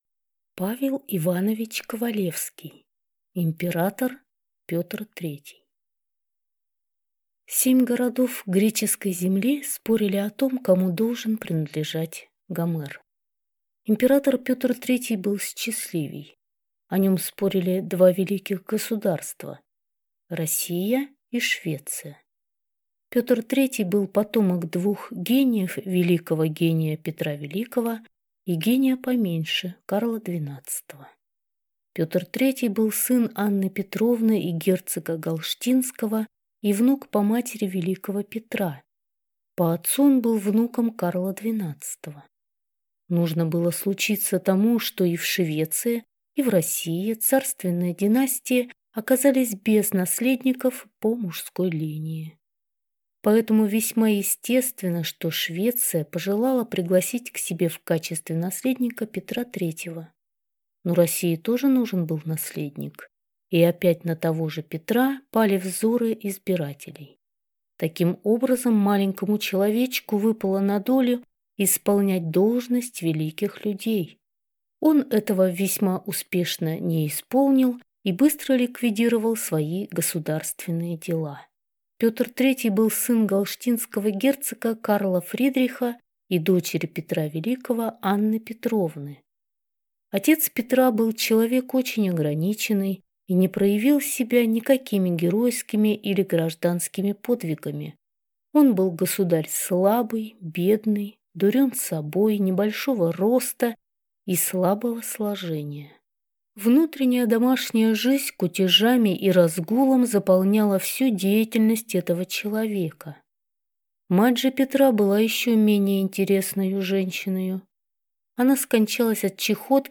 Aудиокнига Император Петр III